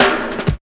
69 SNR+TRAIL.wav